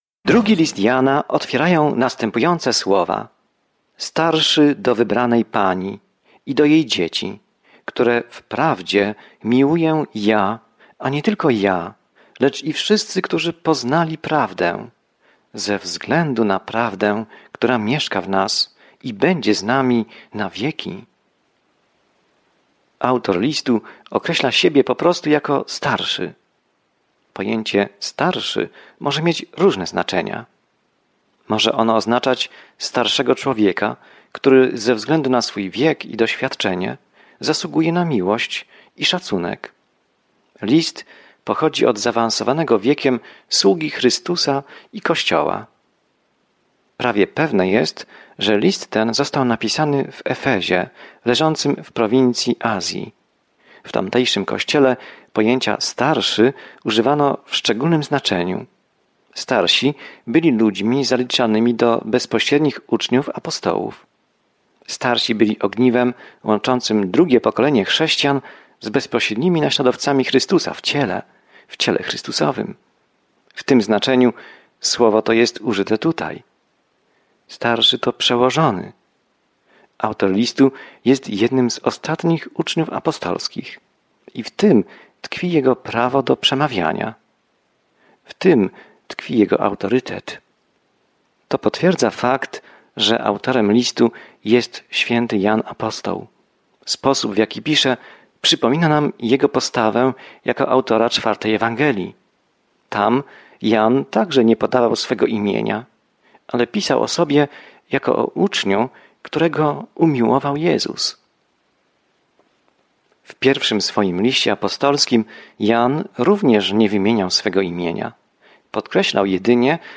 Pismo Święte 2 Jana 1:1-2 Dzień 1 Rozpocznij ten plan Dzień 3 O tym planie Ten drugi list Jana pomaga hojnej kobiecie i lokalnemu kościołowi nauczyć się wyrażać miłość w granicach prawdy. Codziennie podróżuj po 2 Liście Jana, słuchając studium audio i czytając wybrane wersety słowa Bożego.